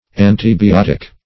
antibiotic \an`ti*bi*ot"ic\ n.